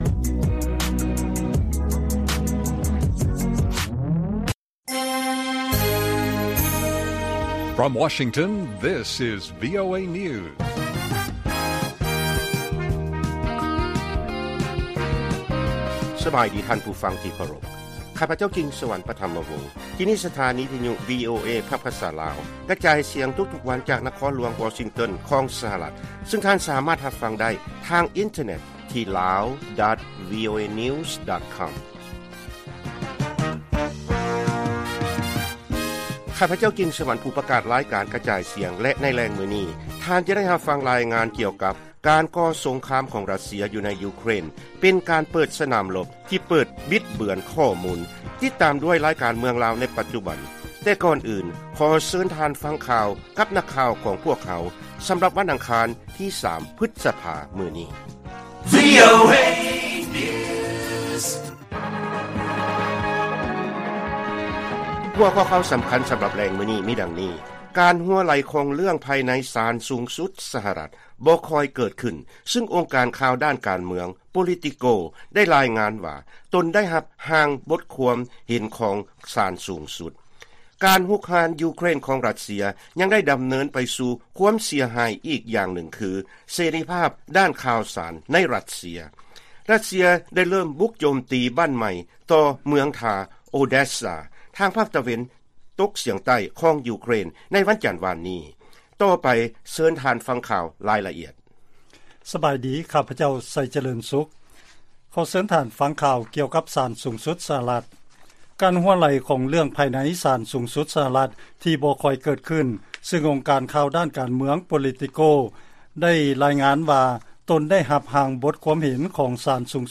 ວີໂອເອພາກພາສາລາວ ກະຈາຍສຽງທຸກໆວັນ, ຫົວຂໍ້ຂ່າວສໍາຄັນໃນມື້ນີ້ມີ: 1. ສານສູງສຸດ ສຫລ ຈະຕ່າວປີ້ນ ສິດໃນການແທ້ງລູກ, 2. ການໂຄສະນາຊວນເຊື່ອ ເປັນໄພຂົ່ມຂູ່ຕໍ່ເສລີພາບຂອງສື່ມວນຊົນ ໃນທົ່ວໂລກ, ແລະ 3. ການໂຈມຕີທາງອາກາດຂອງຣັດເຊຍ ຖືກເມືອງທ່າ ໂອແດສຊາ ທາງພາກຕາເວັນຕົກສຽງໃຕ້ຂອງຢູເຄຣນ.